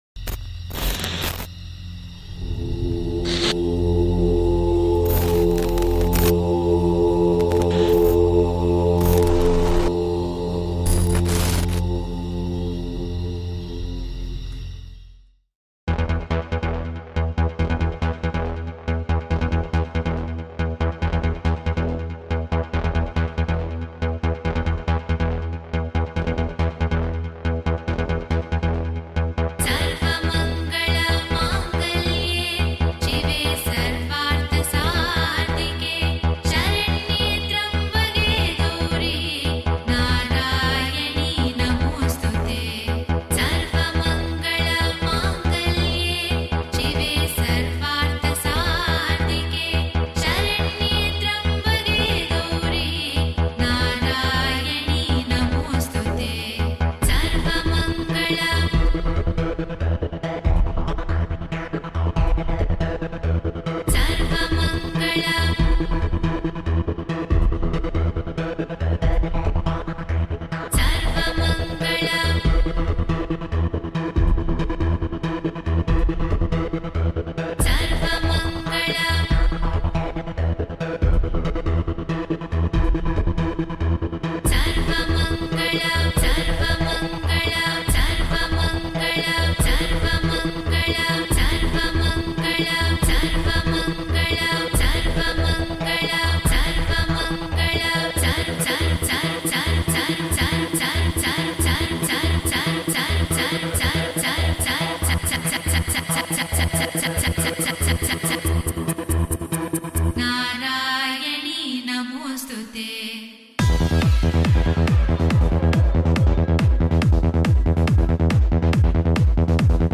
High quality Sri Lankan remix MP3 (6.3).
high quality remix